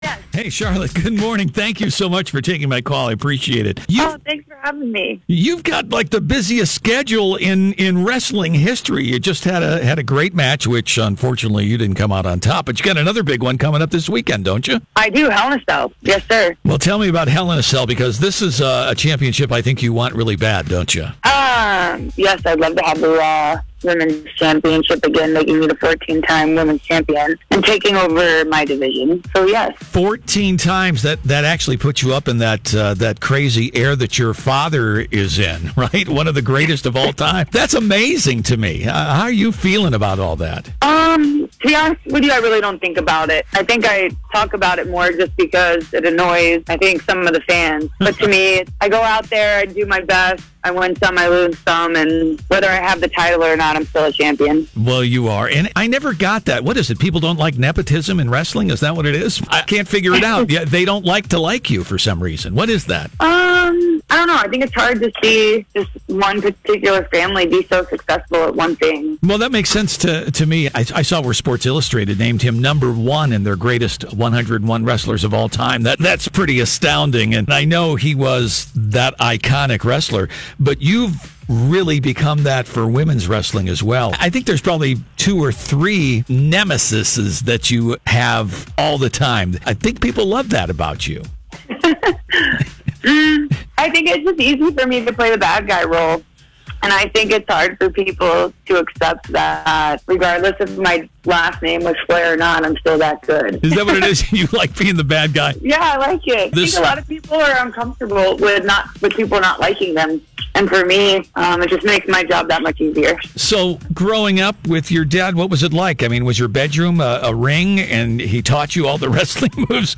WWE’s Charlotte Flair was a guest on the Mix Morning Show Friday